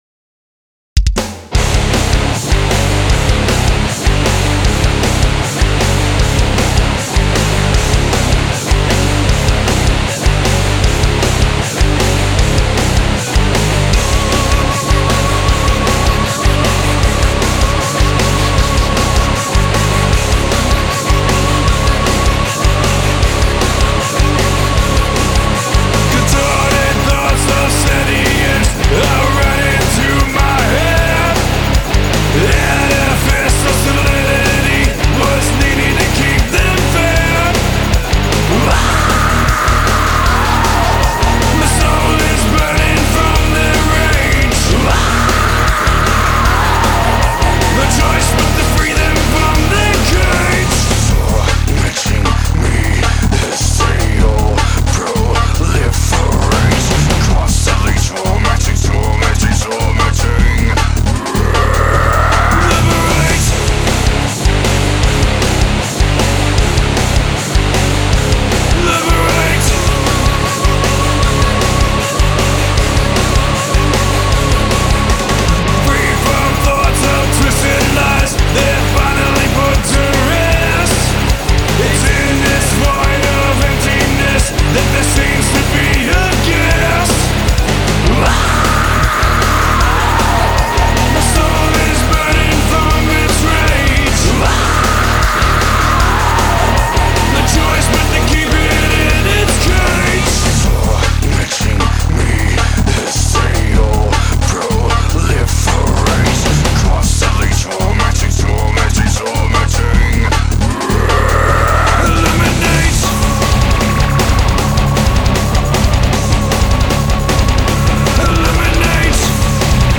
guitars & programming